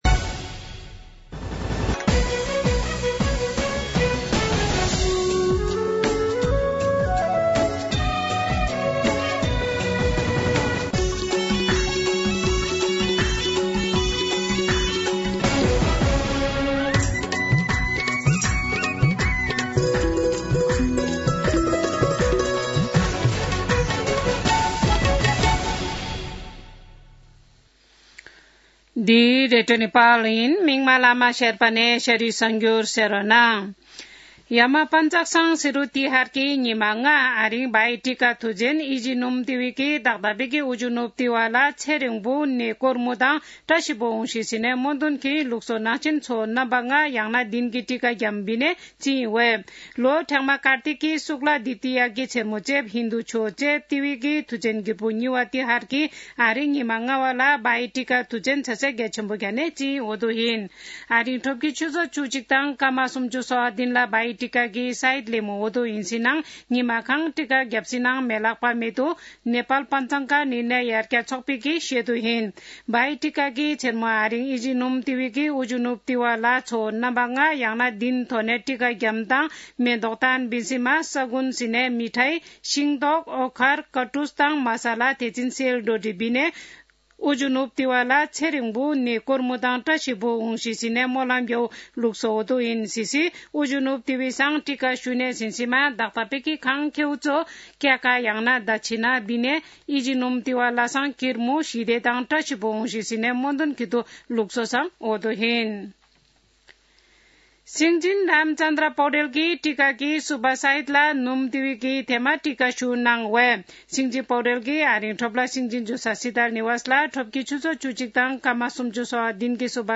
शेर्पा भाषाको समाचार : १९ कार्तिक , २०८१
sherpa-News-.mp3